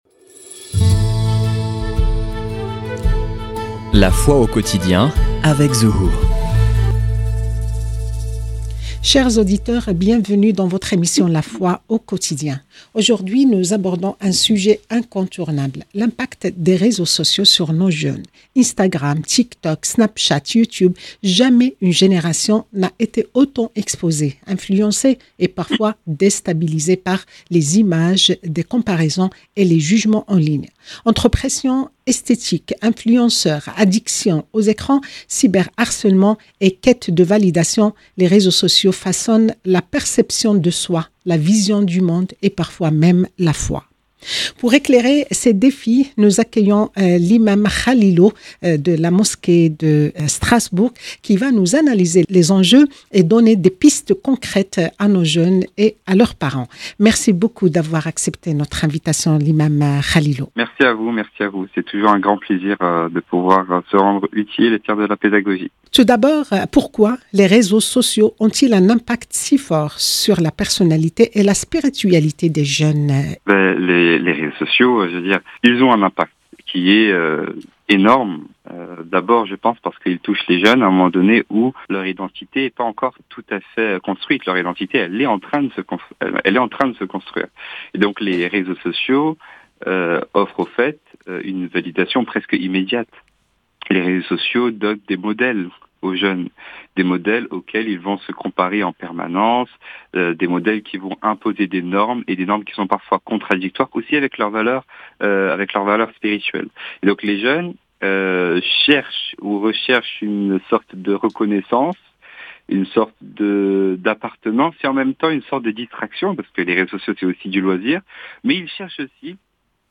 Dans cette émission De Paris avec vous